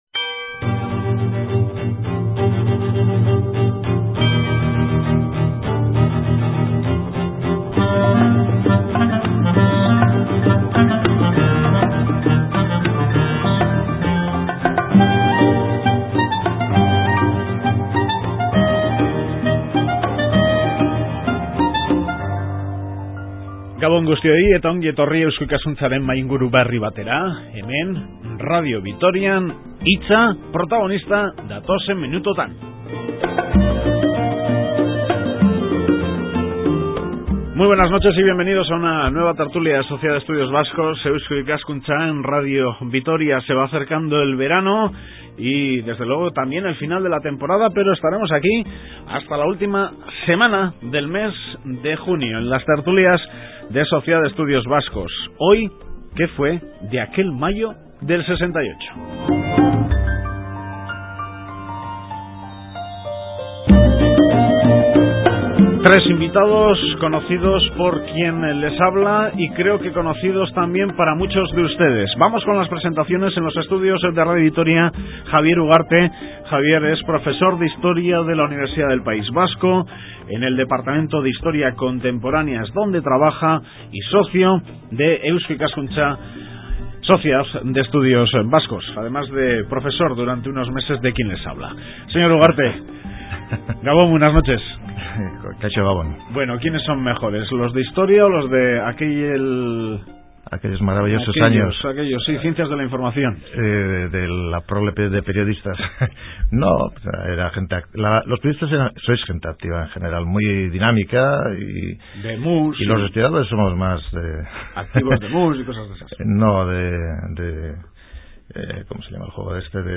Se cumplen 40 años del conocido como Mayo de 1968 o Mayo francés. Hablaremos con testigos e historiadores de dicho movimiento para conocer un poco más aquella efeméride que conmocionó al mundo.